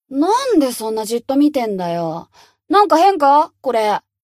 贡献 ） 分类:蔚蓝档案语音 协议:Copyright 您不可以覆盖此文件。